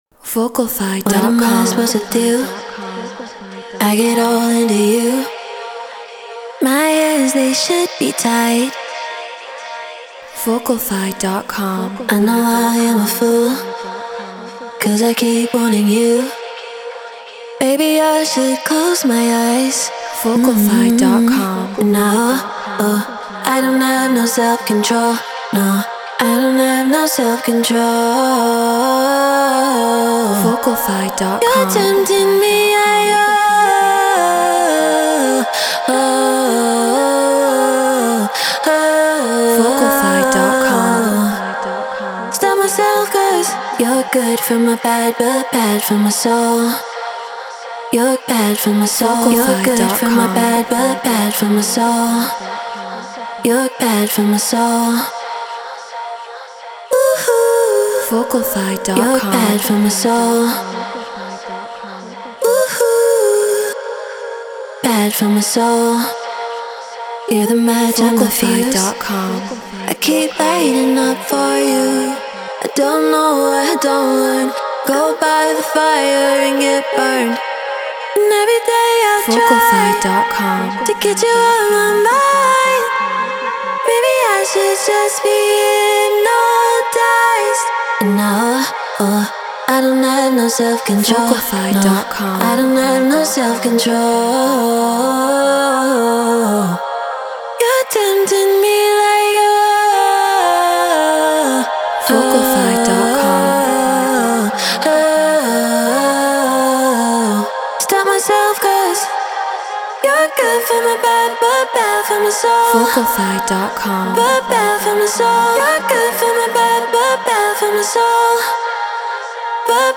Drum & Bass 174 BPM Fmin
Shure SM7B Scarlett 2i2 4th Gen Ableton Live Treated Room